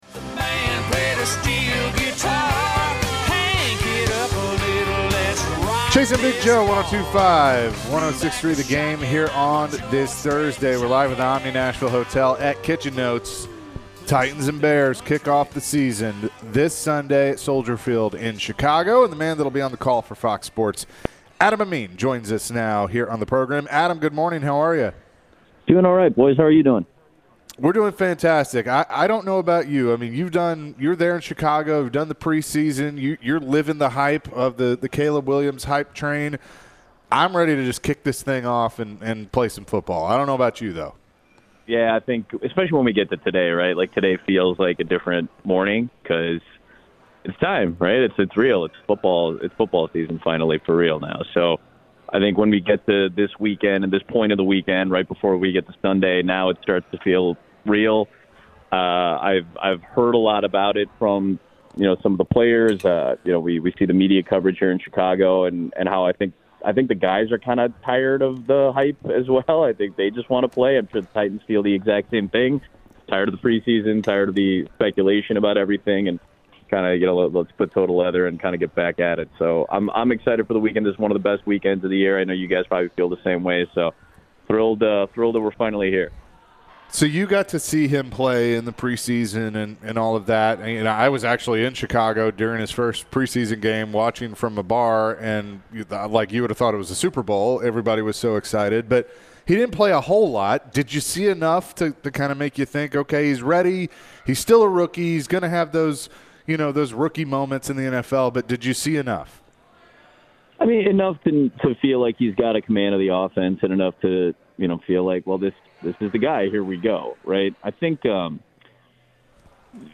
Fox Sports commentator Adam Amin joined the show to discuss the Titans matchup with the Bears on Sunday. Amin provided insight on some of his preparation for calling the game this Sunday.